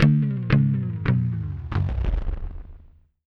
GUITARFX10-L.wav